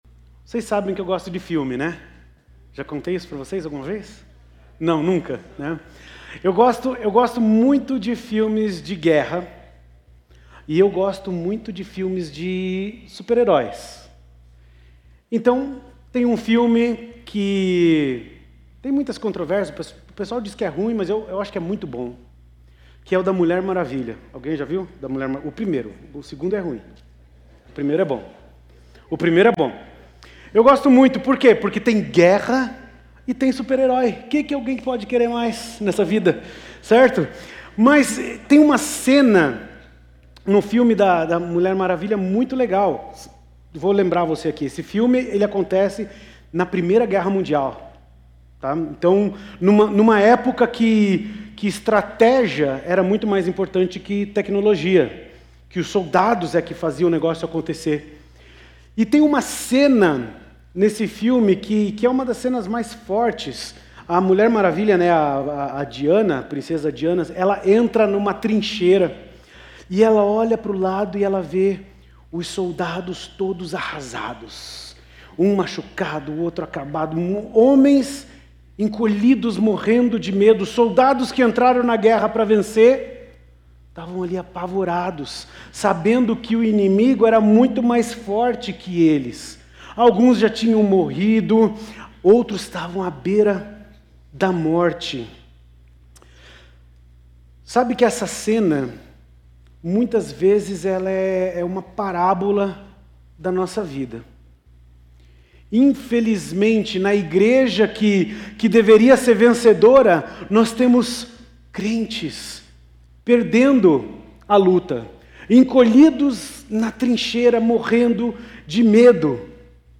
na Igreja Batista do Bacacheri